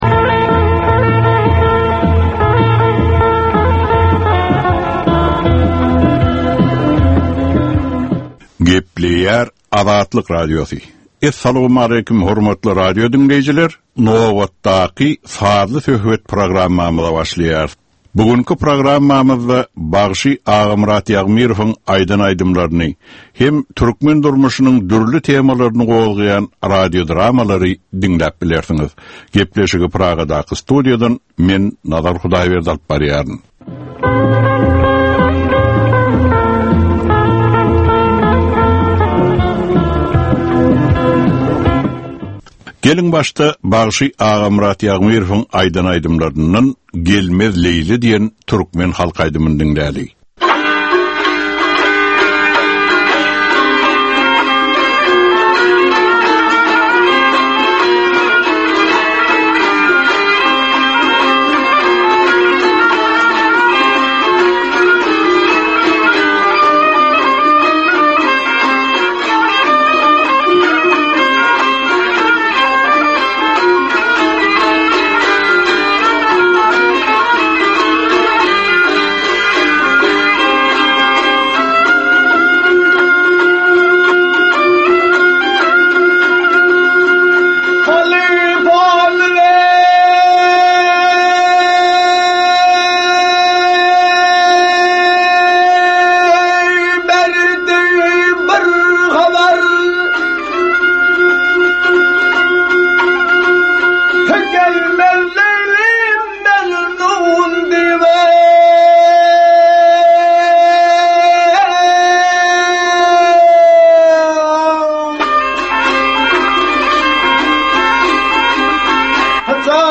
Türkmeniň käbir aktual meseleleri barada sazly-informasion programma.